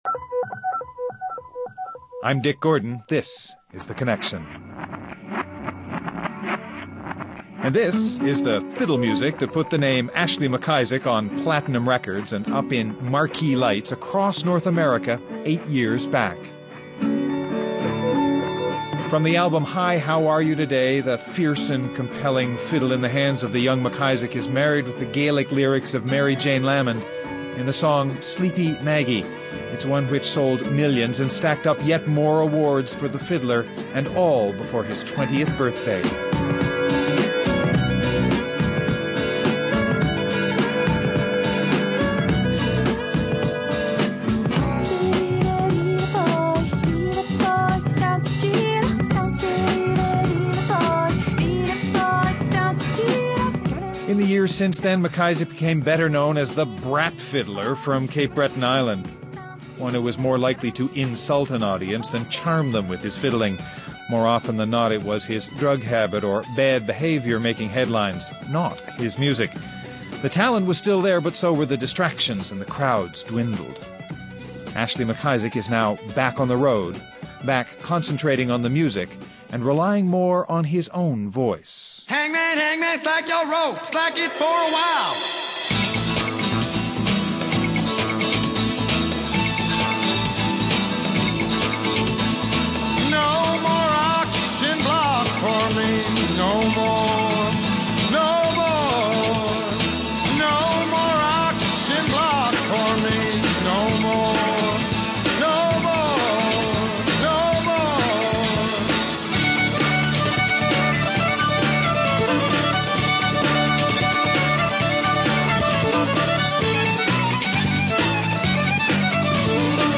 But he’s back, this time with a self-titled CD that blends traditional Cape Breton fiddling with his own bluesy vocals. Ashley MacIsaac: ready for his comeback, and my guest.
Guests: Ashley MacIsaac, Cape Breton fiddler